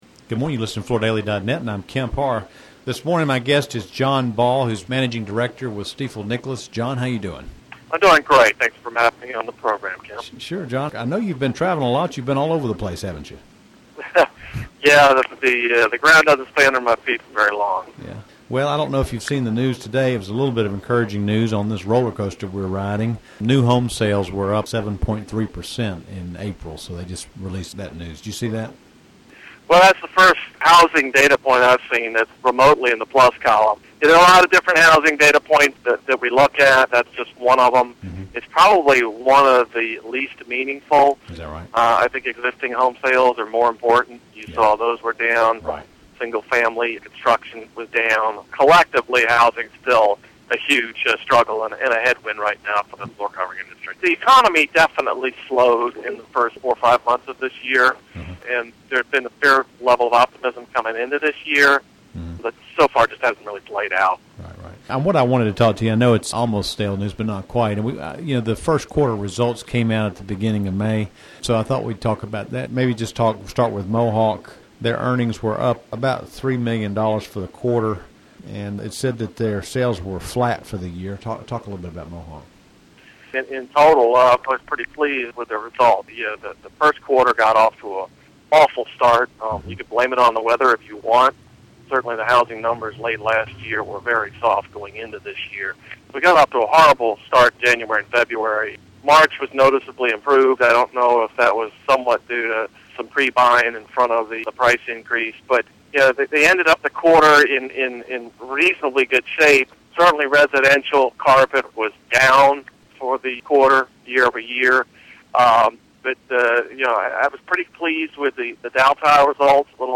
Listen to the interview to hear a quick summary of Mohawk, Armstrong and Interface's performance and outlook.